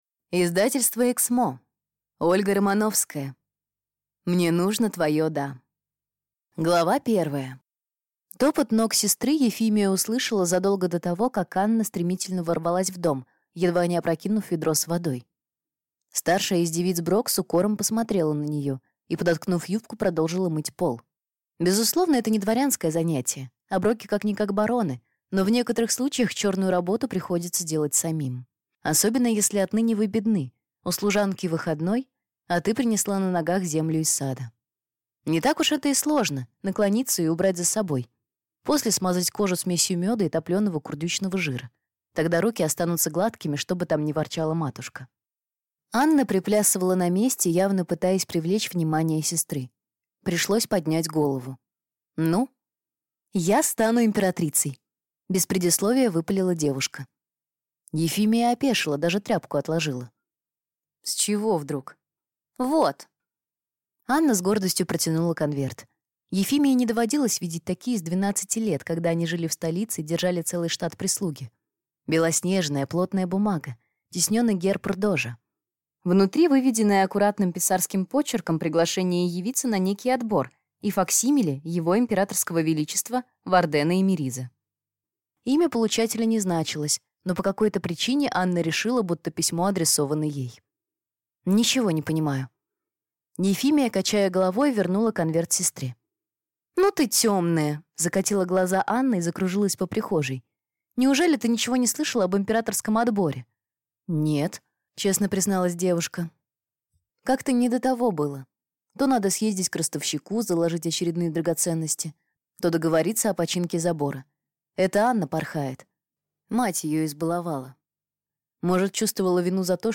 Аудиокнига Мне нужно твое «да» | Библиотека аудиокниг